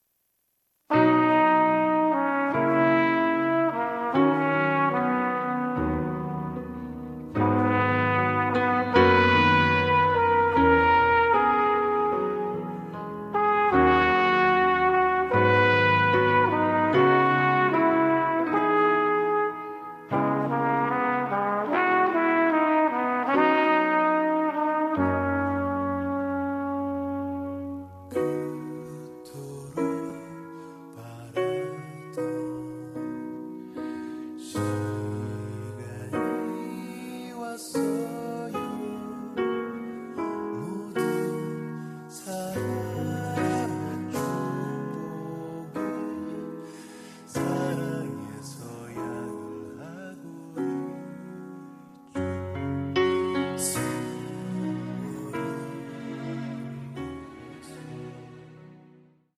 음정 -1키
장르 가요 구분